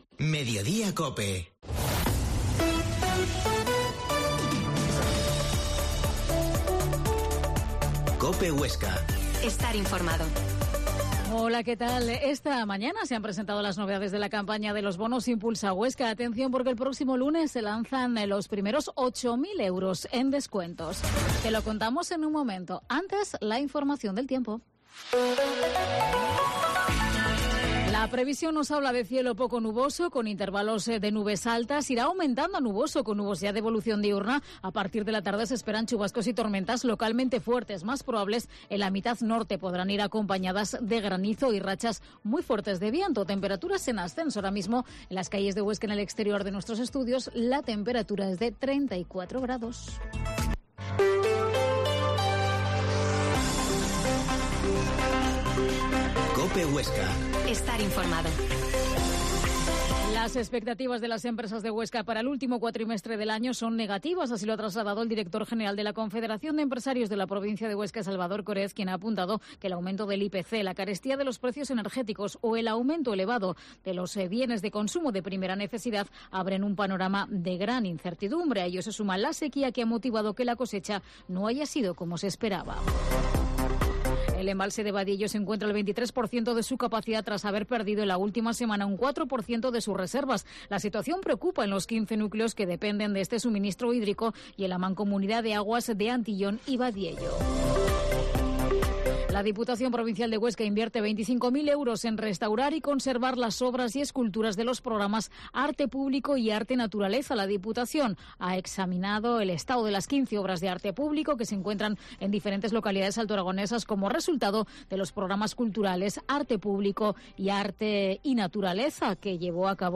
Mediodía en COPE Huesca 13.50h Entrevista a Rosa Gerbás, concejala de Desarollo de Huesca